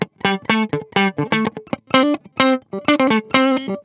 11 GuitarFunky Loop A.wav